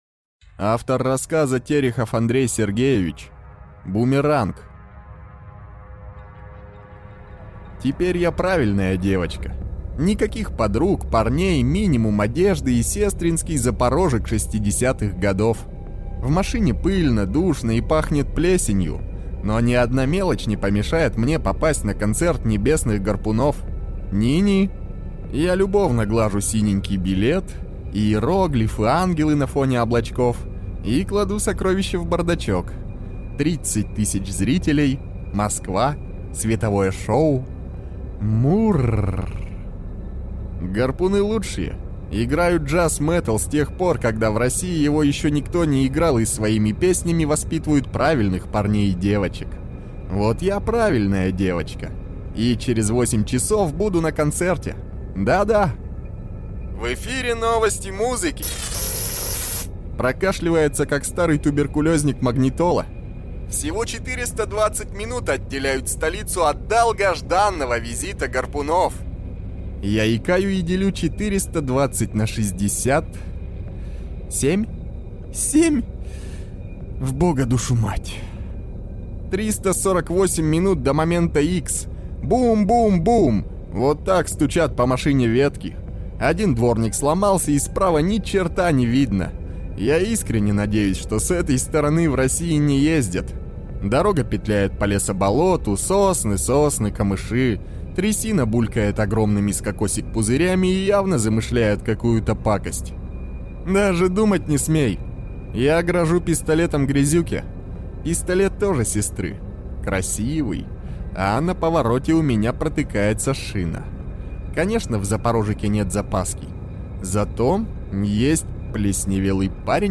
Аудиокнига Бумеранг | Библиотека аудиокниг